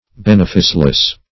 Search Result for " beneficeless" : The Collaborative International Dictionary of English v.0.48: Beneficeless \Ben"e*fice*less\, a. Having no benefice.